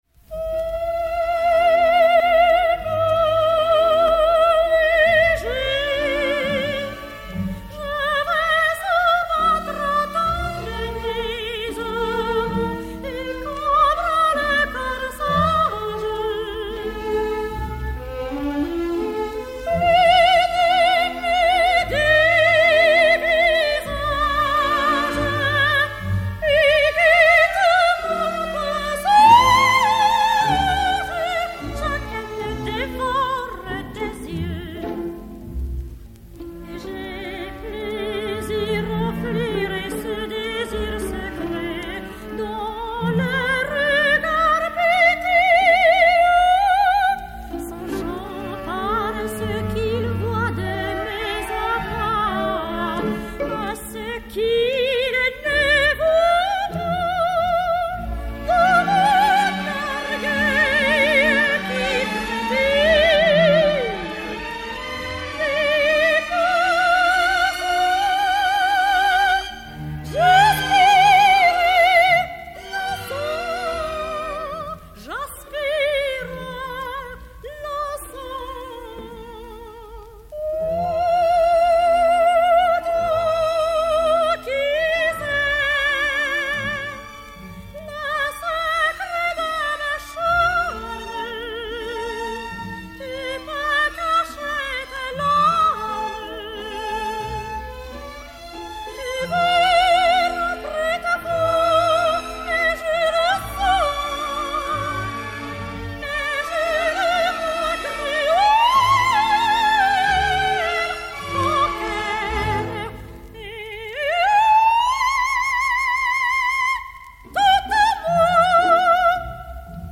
soprano français